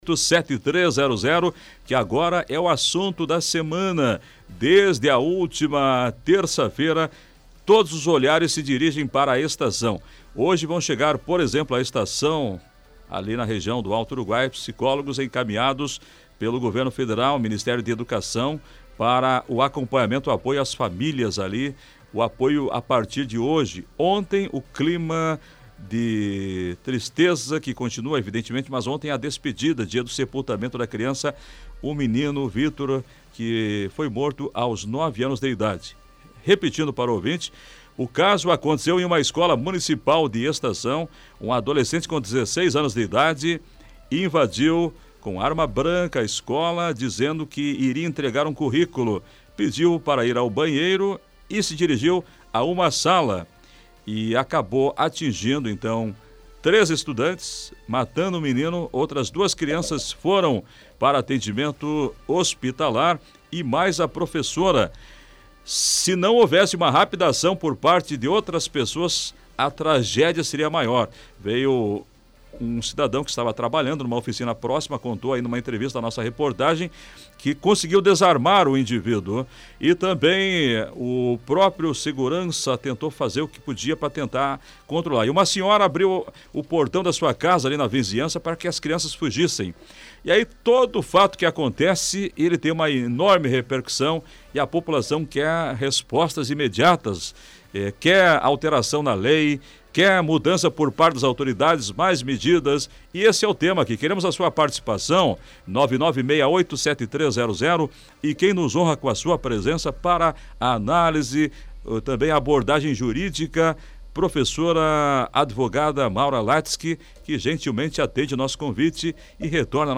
Os ouvintes participaram, com opiniões e indagações. Ouça a entrevista na íntegra